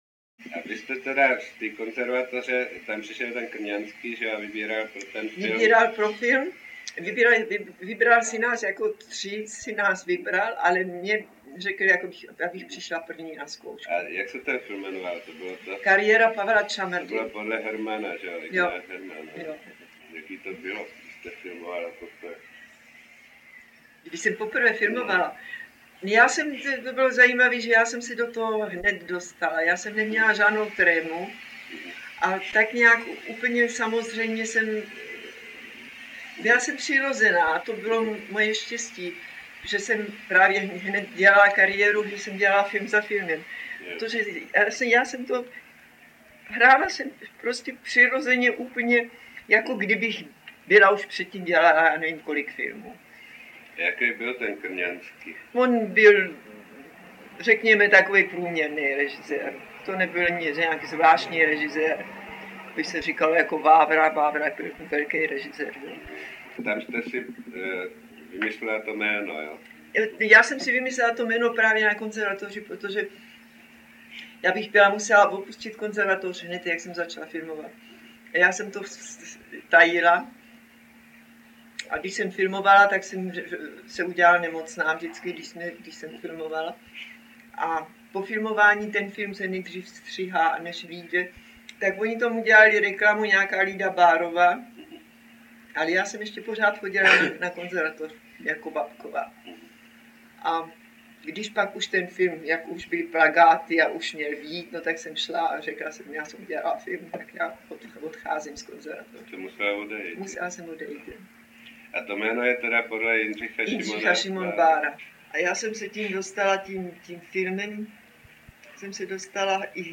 Pravdivá zpověď hvězdy předválečného a válečného filmu. Autentický zvukový záznam jejího přiznání z roku 1982.
Audio kniha
Zřejmě poprvé (a nejspíš naposled) tu Lída Baarová mluví opravdu upřímně. Josef Škvorecký si z hovorů pořizoval amatérský zvukový záznam, který hodlal literárně zpracovat.
• InterpretLída Baarová, Josef Škvorecký